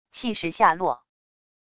气势下落wav音频